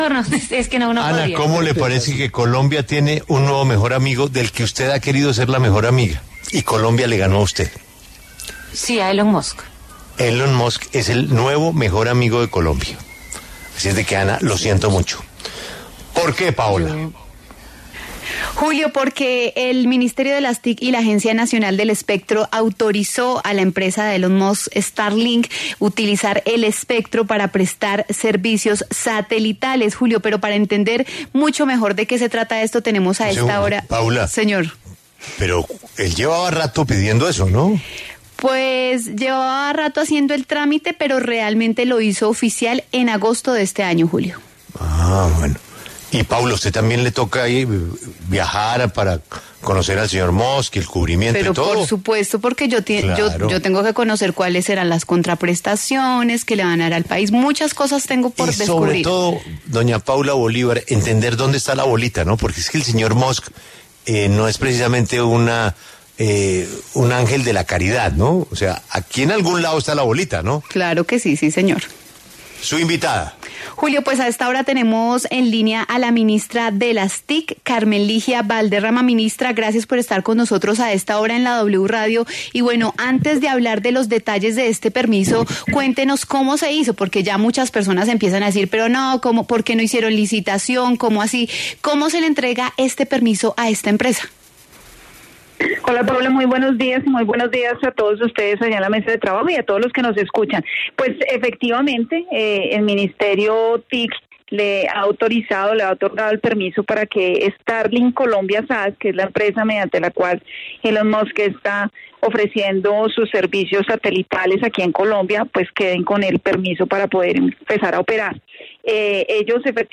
En diálogo con La W, la ministra de las Tecnologías de la Información y las Comunicaciones (TIC), Carmen Ligia Valderrama Rojas, se refirió al permiso otorgado a la empresa Starlink, del magnate de la tecnología Elon Musk, para el uso de espectro para radiocomunicaciones por satélite.
Escuche la entrevista completa a la ministra Carmen Ligia Valderrama en La W: